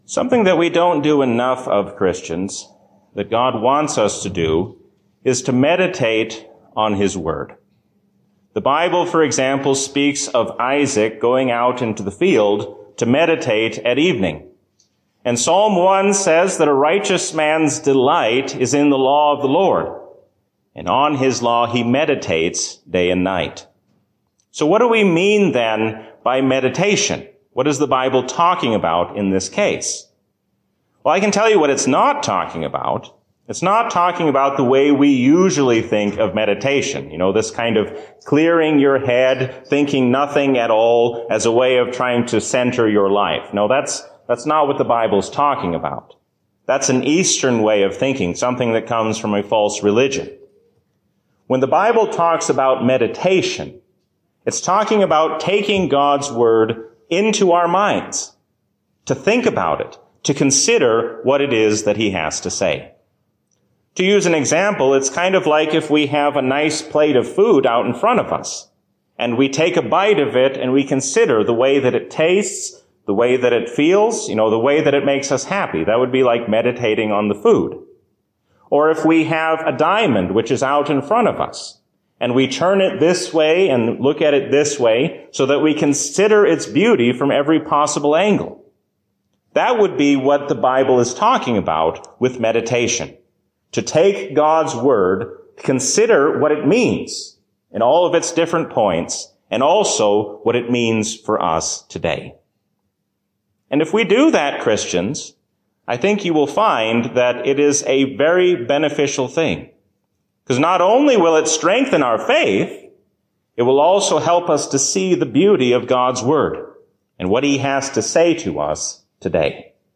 A sermon from the season "Trinity 2022." God is your loving Father and gives you the strength to face all of life's troubles.